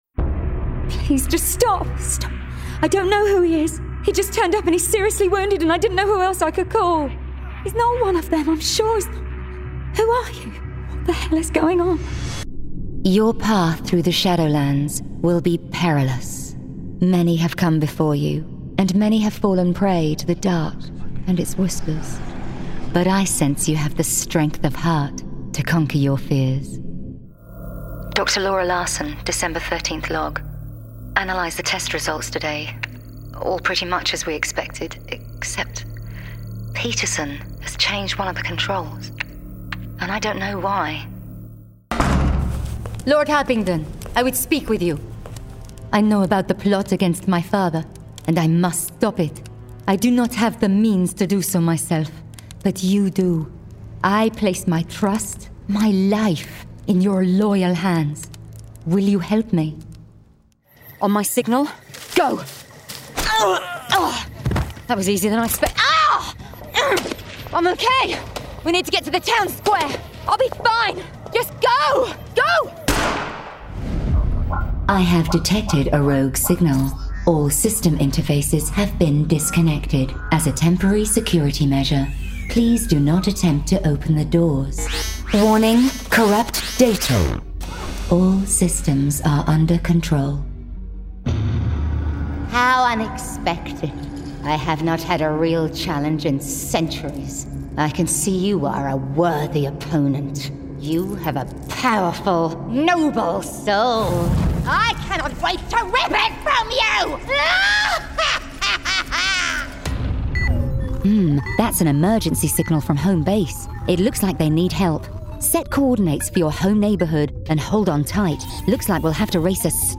Warm, Trustworthy, Conversational, Characters, Comedy, Versatile
british rp | natural
GAMING 🎮
Game_Reel_Edit.mp3